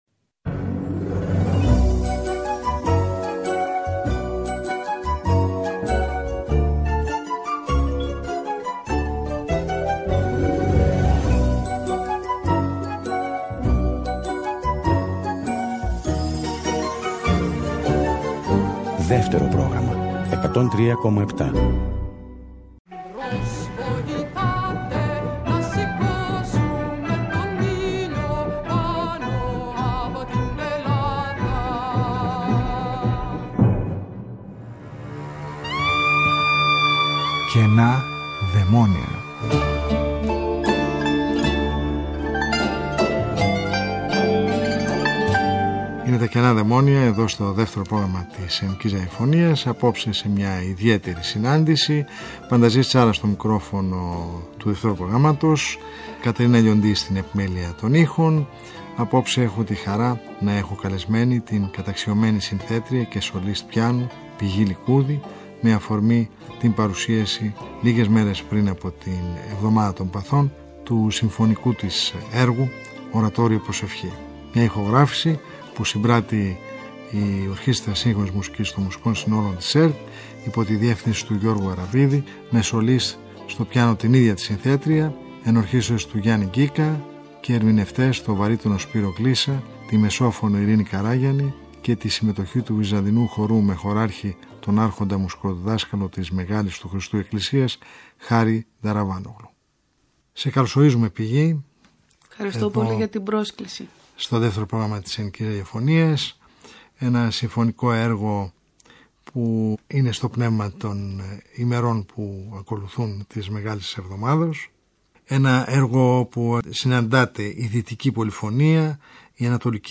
Πρόκειται για ένα μουσικό έργο όπου συναντώνται η Δυτική πολυφωνία, οι Ανατολικοί δρόμοι και το Βυζαντινό μέλος σε μια ισορροπία που το καθιστά απόλυτα Ελληνικό
Ένα Ορατόριο, βασισμένο στην ορθόδοξη υμνολογία των Δεσποτικών και Θεομητορικών εορτών καθώς και στην ζωντανή λαϊκή παράδοση της Μικράς Ασίας και της Κύπρου.